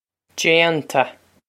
Déanta Jain-ta
This is an approximate phonetic pronunciation of the phrase.